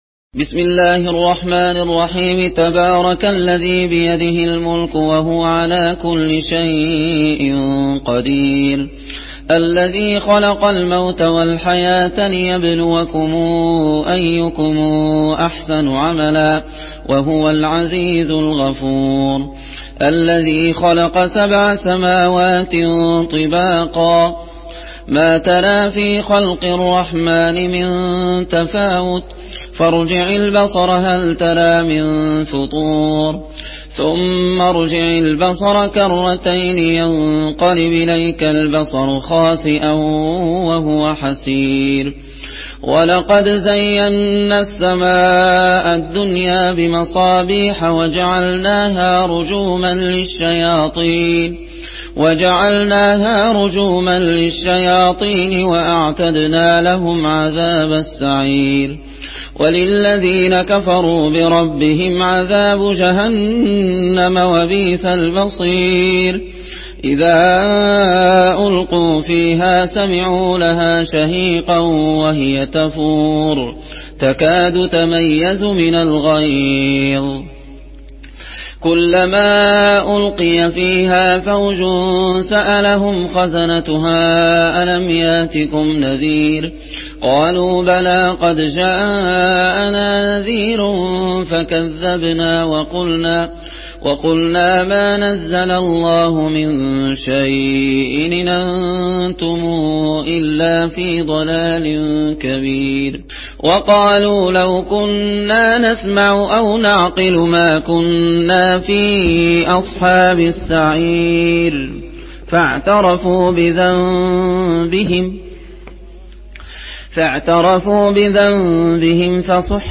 ডাউনলোড করুন এবং কুরআন শুনুন mp3 সম্পূর্ণ সরাসরি লিঙ্ক